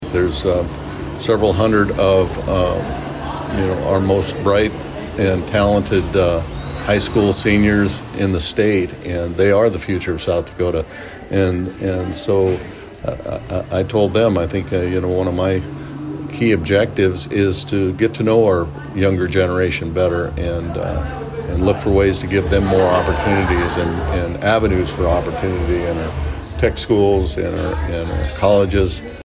VERMILLION, S.D.(KYNT)- South Dakota Governor Larry Rhoden and Representative Dusty Johnson spoke at South Dakota Girls State on the University of South Dakota campus in Vermillion on Wednesday.